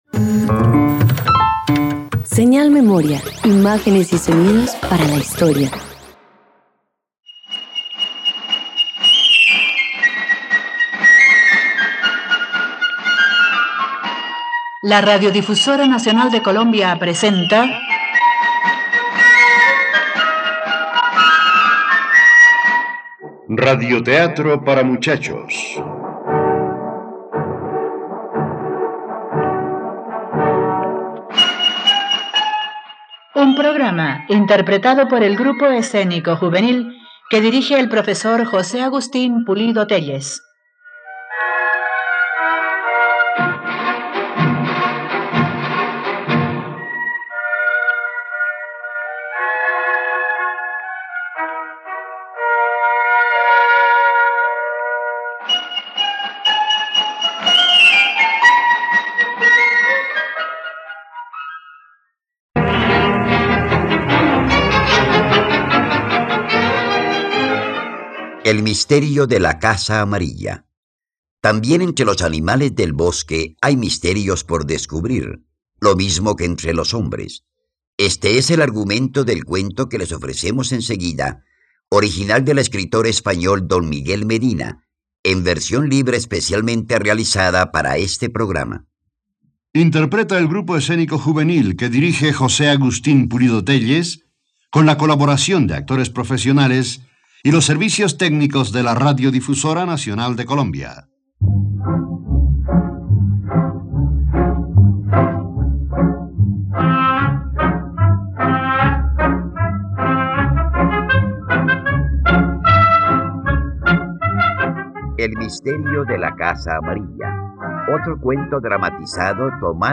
El misterio de la casa amarilla - Radioteatro dominical | RTVCPlay